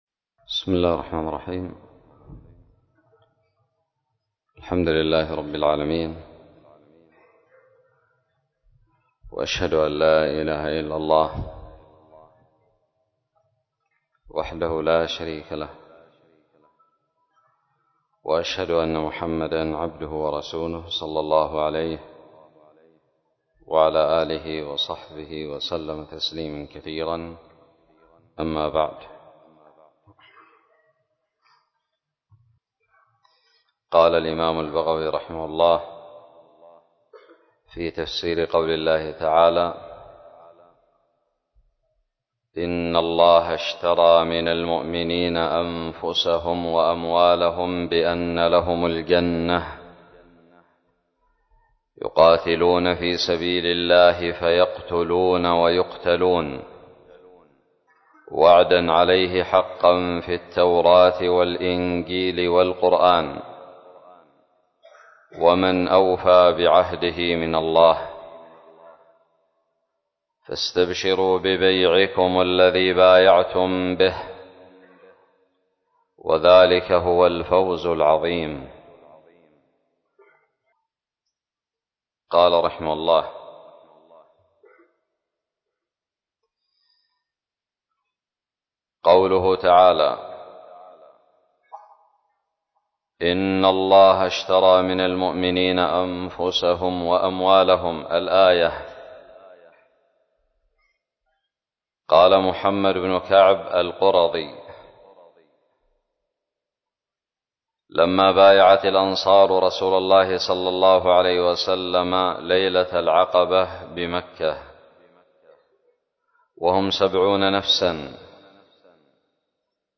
الدرس الثامن والأربعون من تفسير سورة التوبة من تفسير البغوي
ألقيت بدار الحديث السلفية للعلوم الشرعية بالضالع